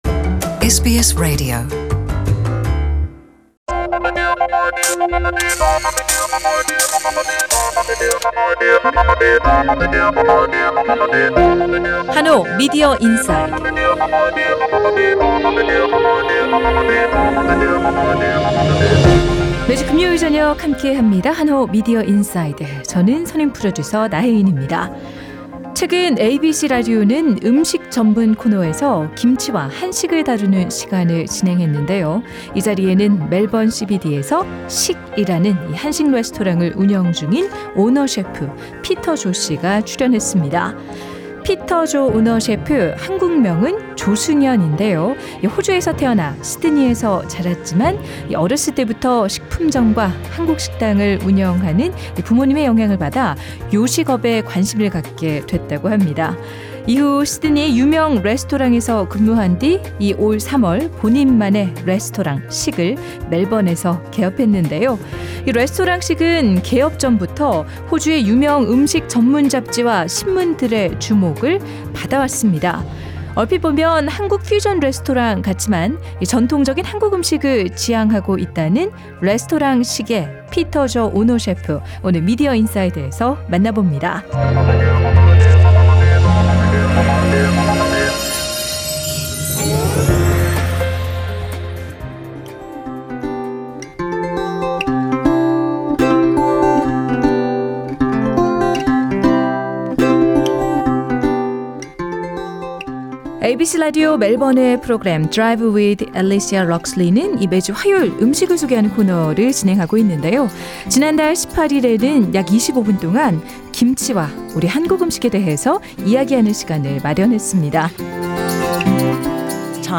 상단의 팟캐스트를 통해 전체 인터뷰를 들으실 수 있습니다.